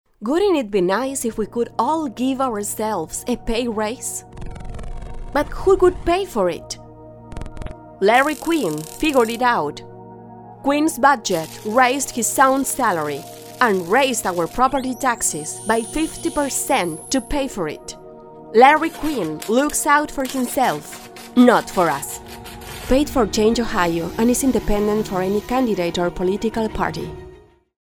Female Spanish Democrat Political Voiceover
Democrat - Spanish